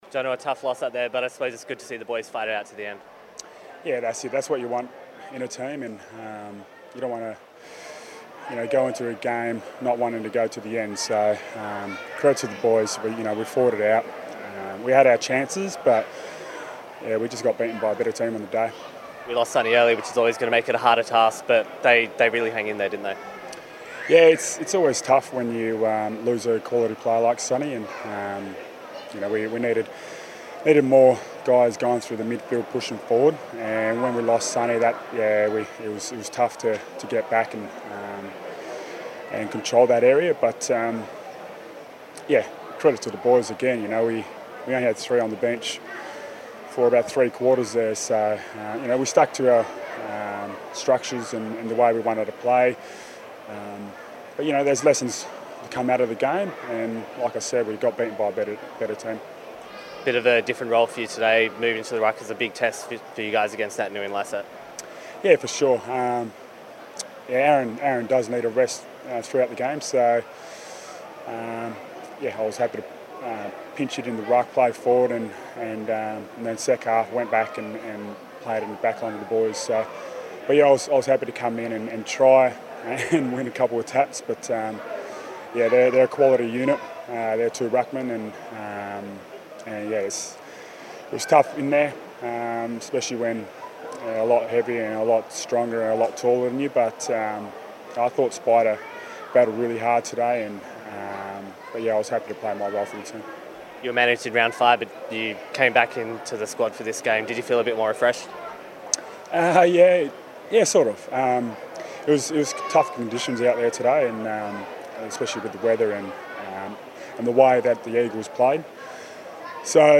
Michael Johnson chats to Docker TV after the Derby on Sunday afternoon.